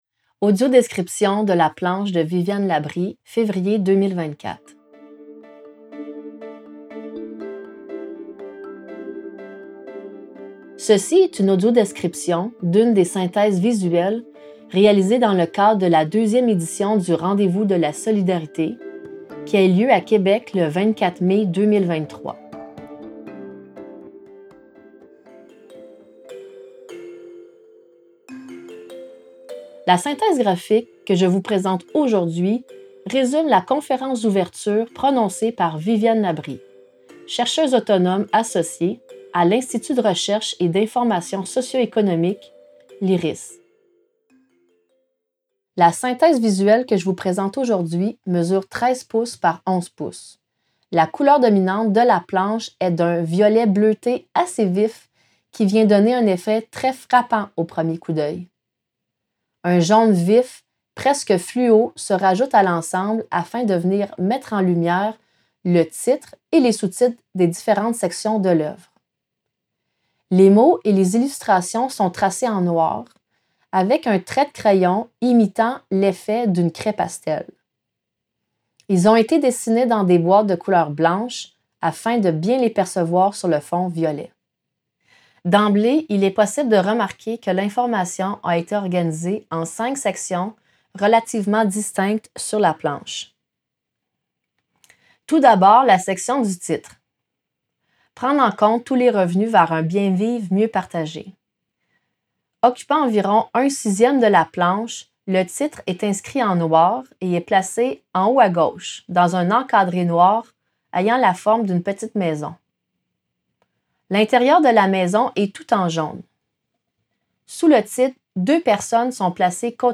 Version auditive de la synthèse des Rendez-vous de la solidarité 2023